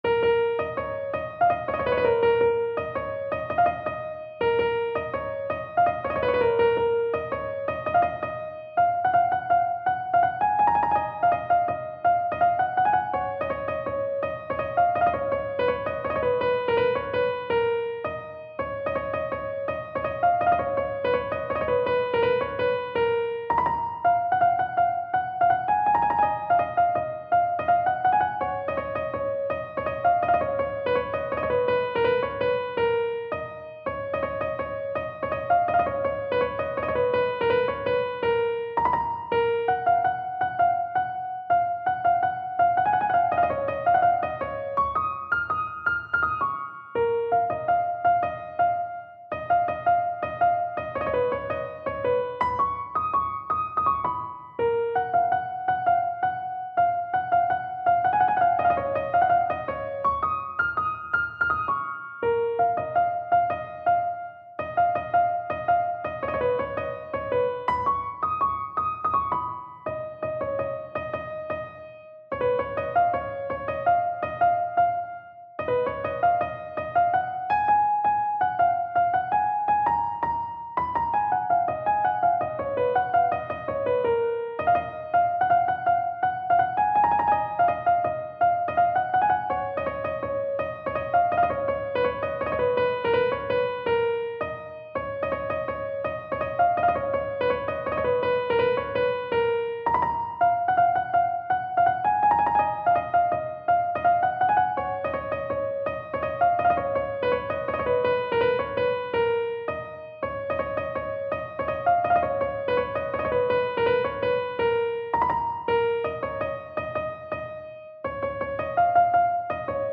تنظیم شده برای کیبورد